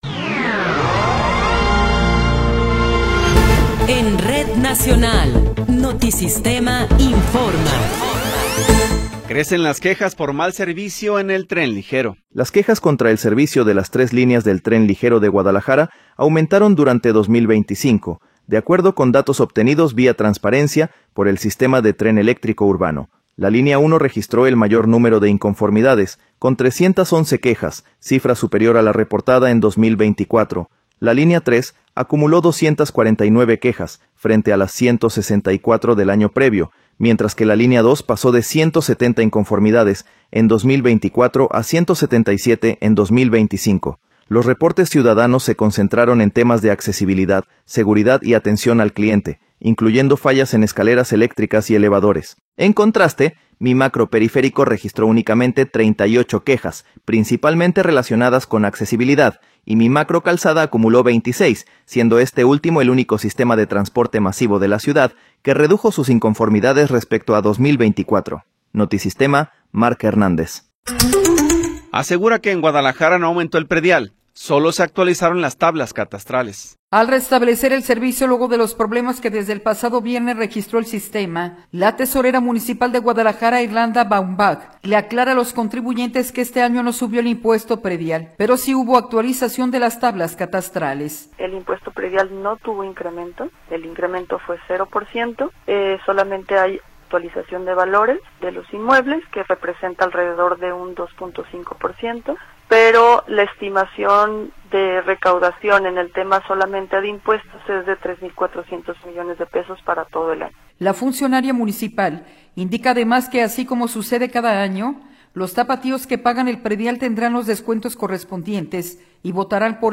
Noticiero 13 hrs. – 7 de Enero de 2026